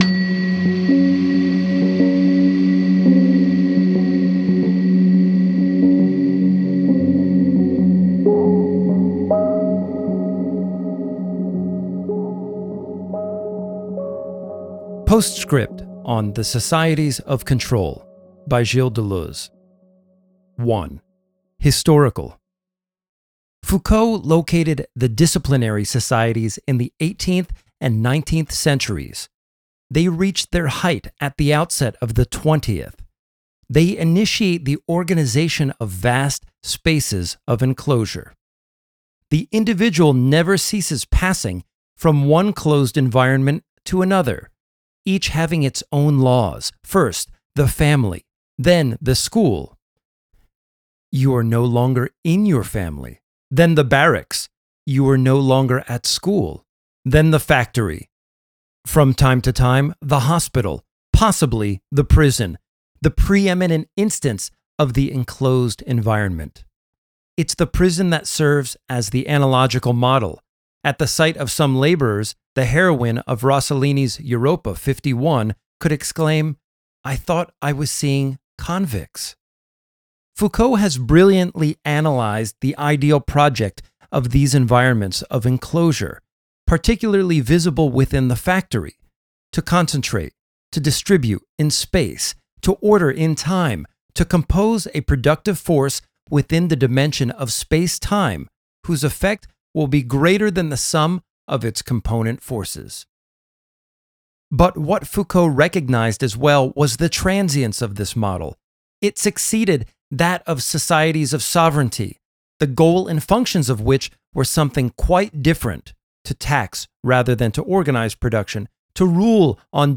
As I promised previously, ere is another short reading to carry you through your holiday blues: Deleuze's 'Postscript' (on which we recently did an episode).